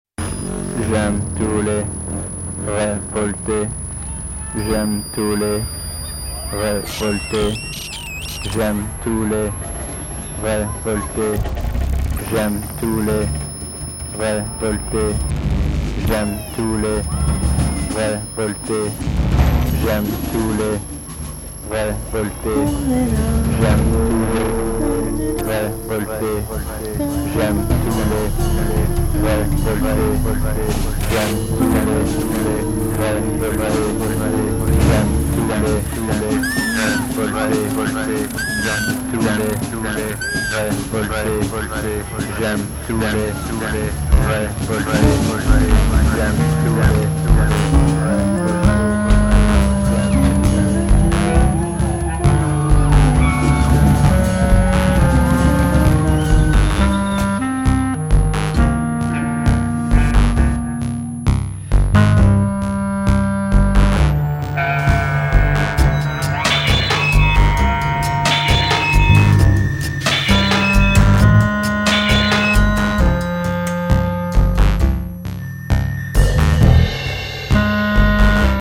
clarinette, cor de basset